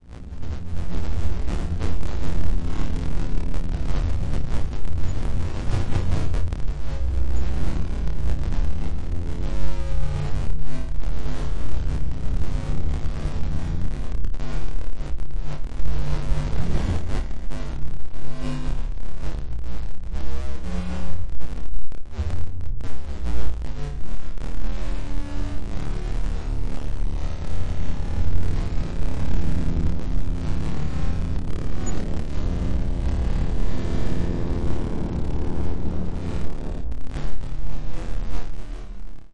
Tag: 电气 电子 出问题 sounddesign 平移 数字 声音效果 静态 无人驾驶飞机 脉冲 黑暗 扭曲 未来 处理 抽象的 sounddesign SFX SCI -fi 噪声